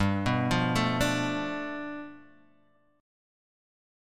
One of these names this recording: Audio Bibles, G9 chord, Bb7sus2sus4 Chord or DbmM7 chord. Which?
G9 chord